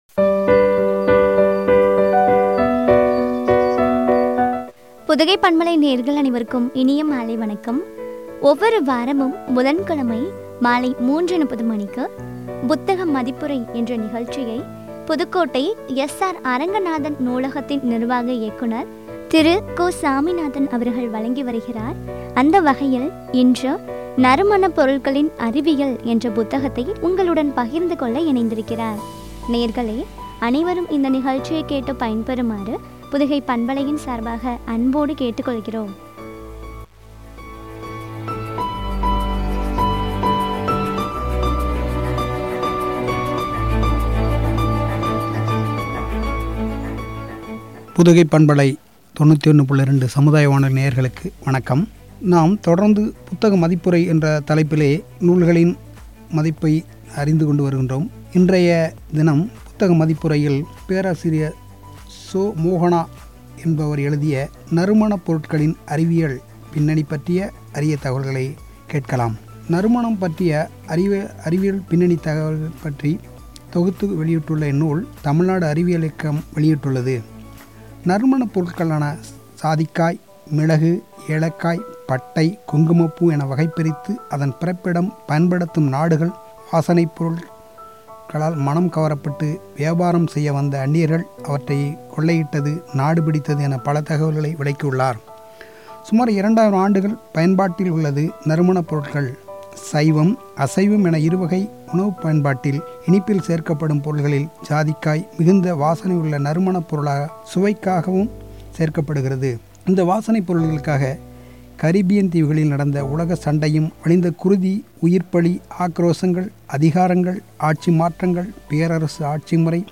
குறித்த வழங்கிய உரையாடல்.